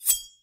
Swords make sheathing/unsheating noises
unsheath.ogg